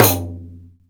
RIQ 3A.WAV